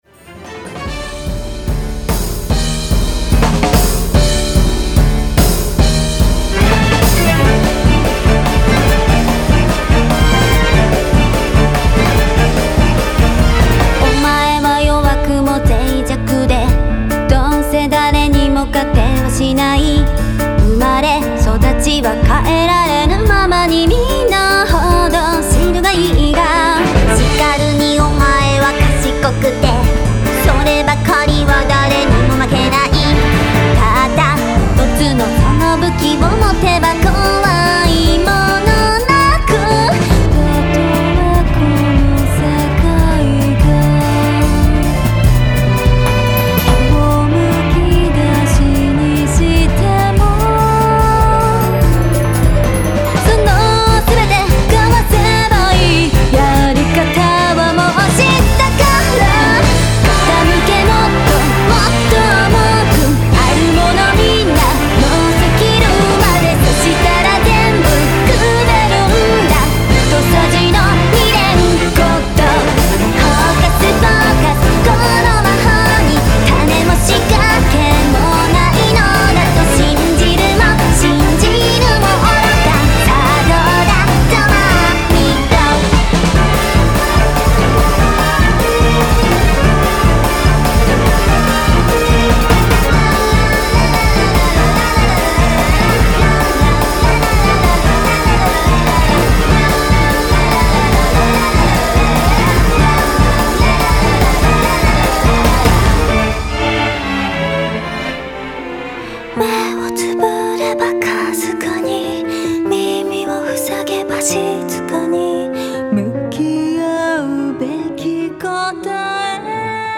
東方マルチジャンルアレンジアルバムです。
重めの主題で、全体的にやや呪わしく。そして幻想的に。
Guitar